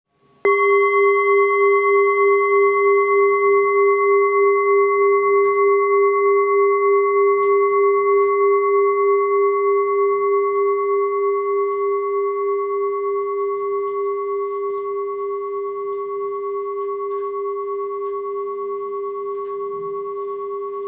Singing bowl, Buddhist Hand Beaten, Moon carved, Antique Finishing, 12 by 12 cm,
Material Seven Bronze Metal
This is a Himalayas handmade full moon singing bowl.